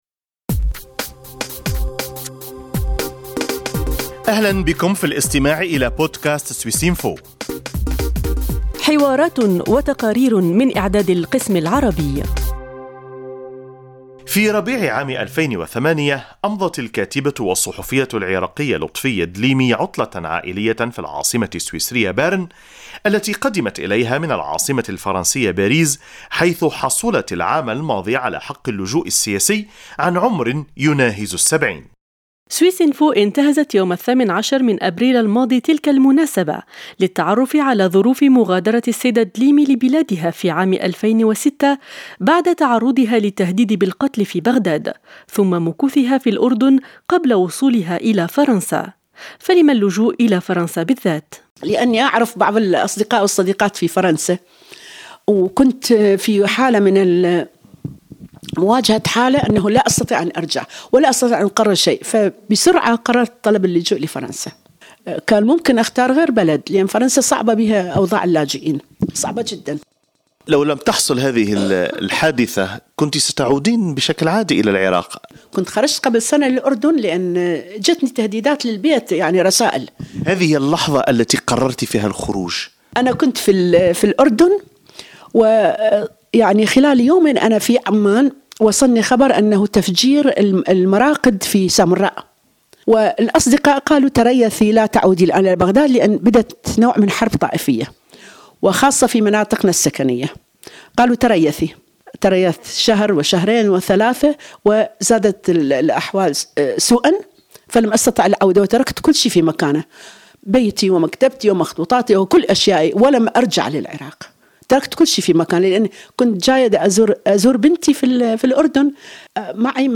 الكاتبة والأديبة العراقية لُطفية الدّليمي في مُقتطفات من حوار متعدّد المحاور تطرقت فيه إلى أوجه مأساوية من الوضع في بلادها، وقساوة منفاها الإضطراري في فرنسا، ورؤيتها لاحتمالات المُستقبل.